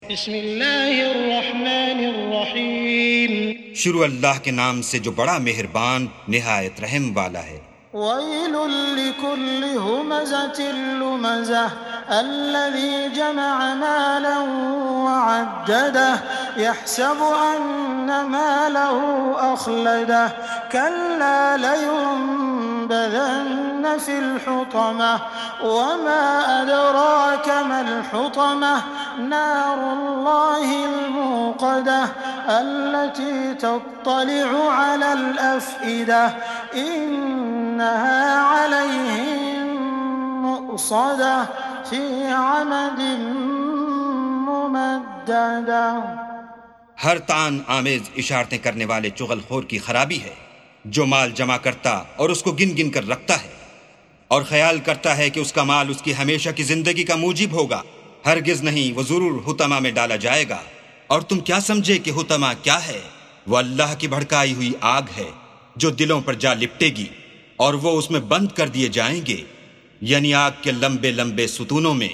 سُورَةُ الهُمَزَةِ بصوت الشيخ السديس والشريم مترجم إلى الاردو